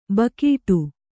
♪ bakīṭu